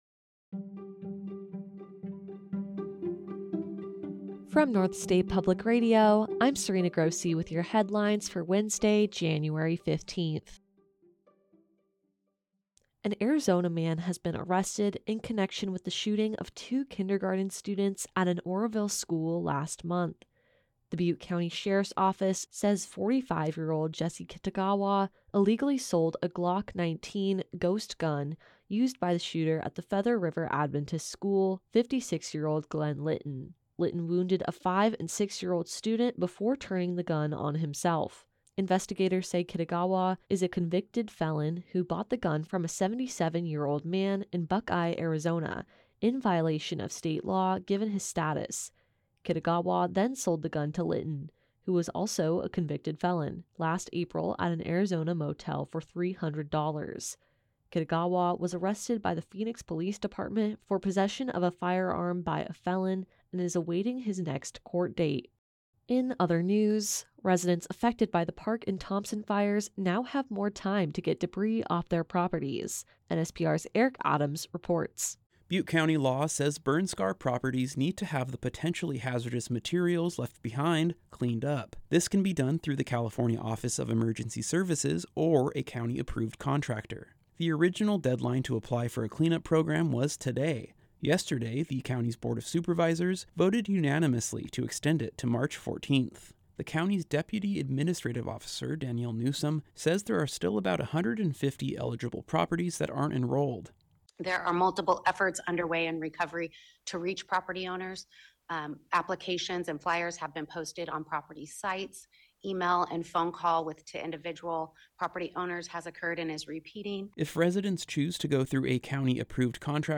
A daily podcast from NSPR featuring the news of the day from the North State and California in less than 10 minutes. Hosted by NSPR Staff, and available at 8:30 a.m. every weekday.